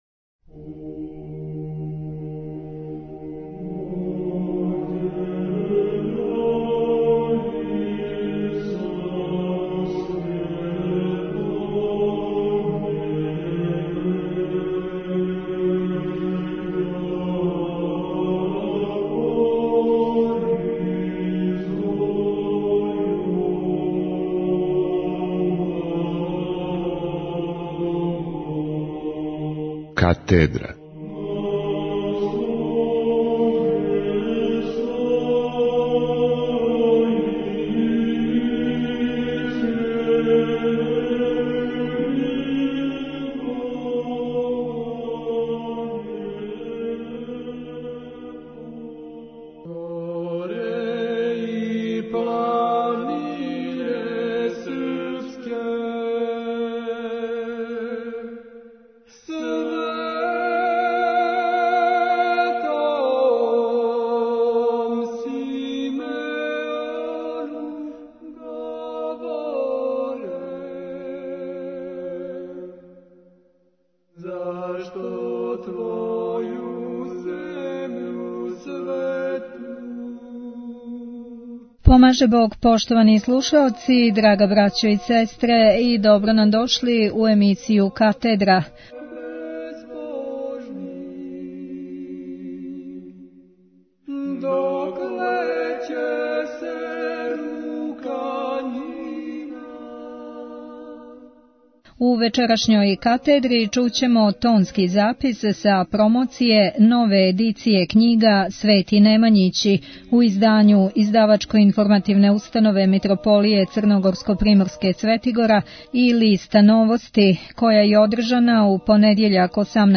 У емисији "Катедра" доносимо тонски запис са промоције нове едиције књига "Свети Немањићи" у издању Издавачко-информативне установе Митрополије Црногорско-приморске "Светигора" и "Новости" која је одржана 18. јануара 2016. године у Медија центру у Београду.